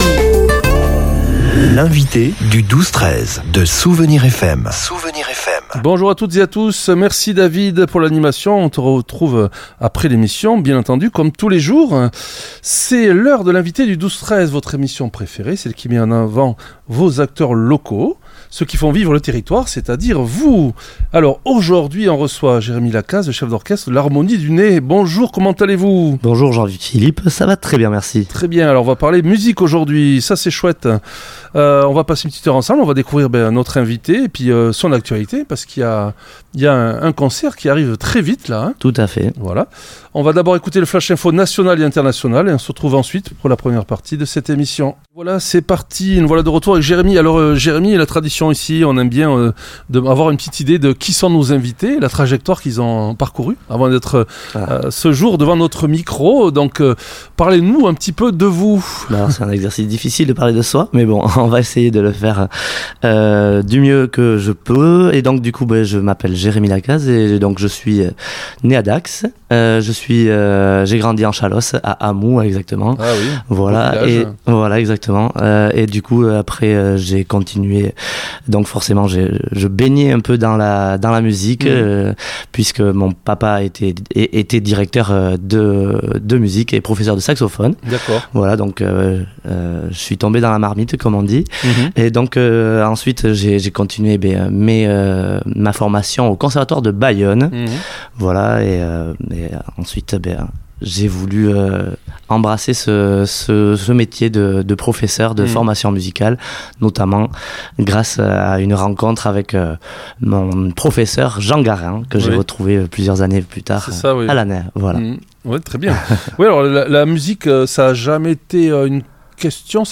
L'entretien s'est focalisé sur l'événement majeur du week-end : le grand concert du samedi 4 avril.